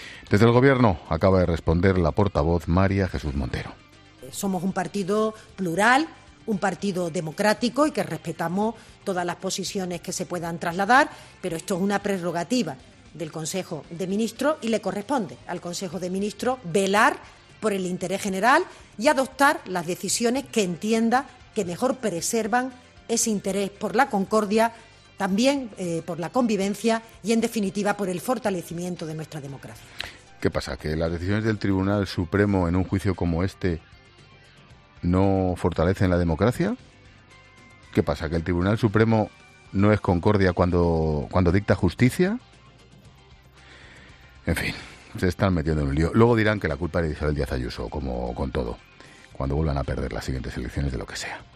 El director de 'La Linterna', Ángel Expósito, ha dedicado unas palabras a la portavoz del Gobierno por su visión de los indultos